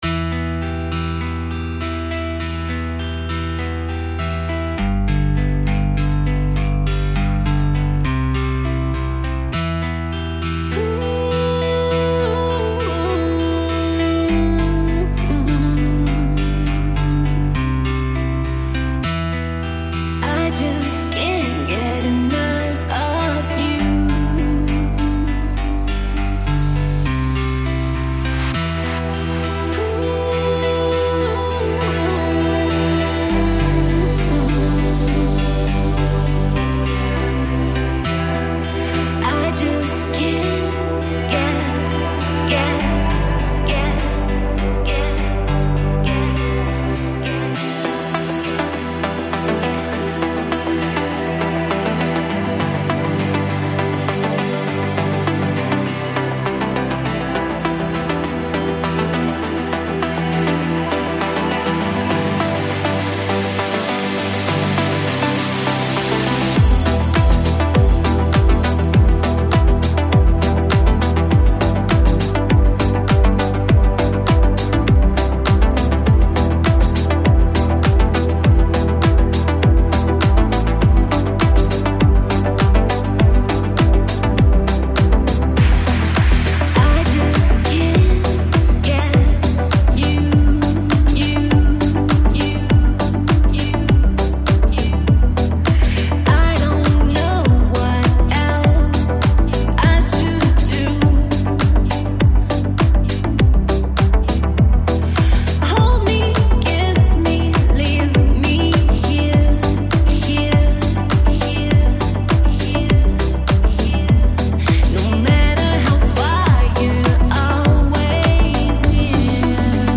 Жанр: Electronic, Lounge, Chill Out, Soulful House